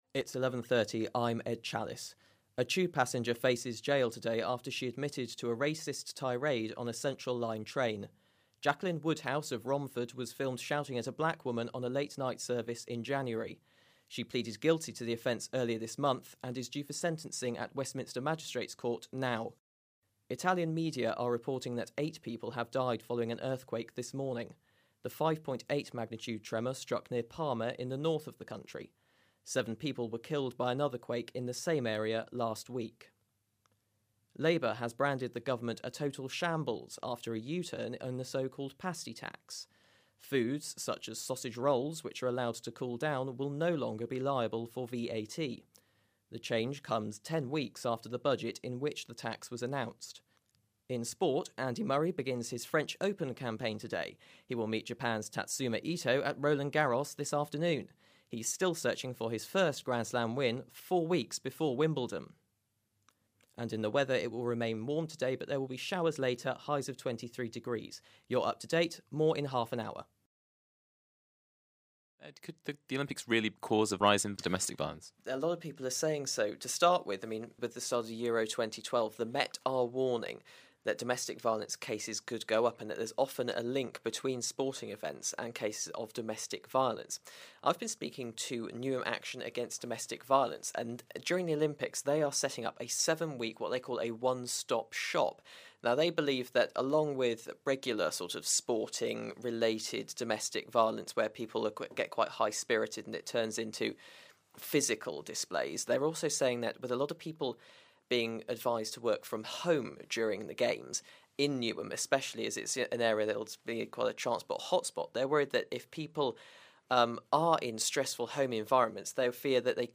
Mixed Demo
Examples of self-written bulletin reading, live two-way and package reporting